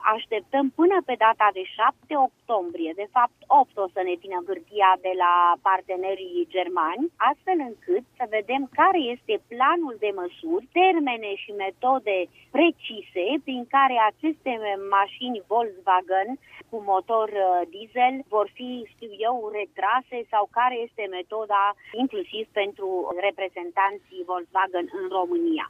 Prezentă la emisiunea „Probleme la Zi” de la Radio România Actualităţi, demnitarul a precizat că după primirea, săptămâna viitoare, de la autorităţile şi reprezentanţii companiei germane a planului de măsuri se va lua o decizie şi în ţara noastră.
În principiu, dacă la verificări se va constata că anumite tipuri de maşini nu respectă standardele euro în vigoare, se va calcula diferenţa de taxă de mediu, care va fi plătită de companie sau de reprezentanţii săi în România, a mai spus Graţiela Gavrilescu: